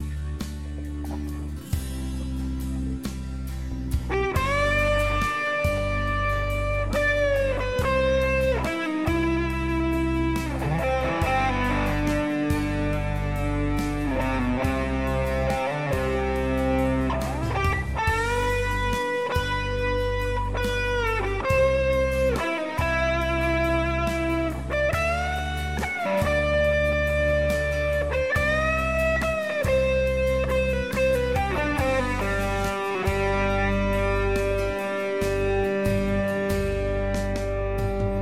Strat on bridge pickup with Seymour Duncan SSL5 pickup
Eventide Time Factor Delay at 440ms at 7% wet signal
A little reverb added in the final mix on the Boss BR600